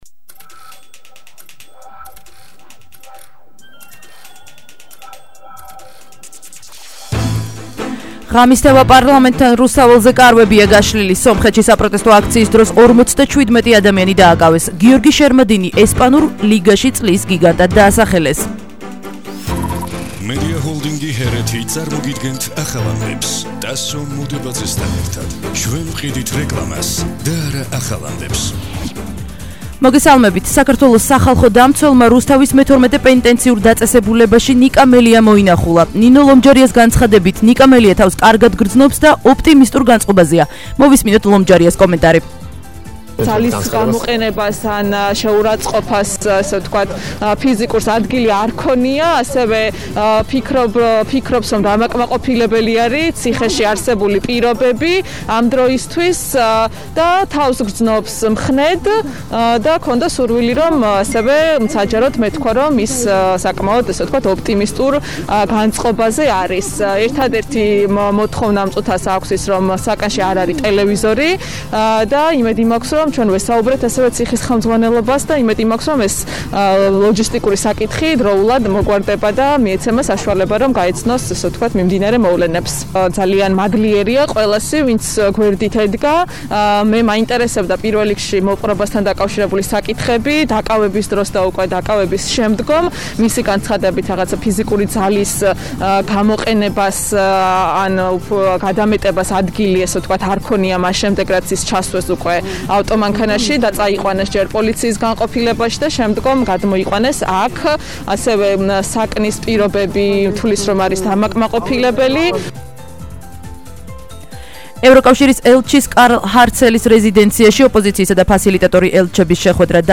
ახალი ამბები 23:00 საათზე –23/02/21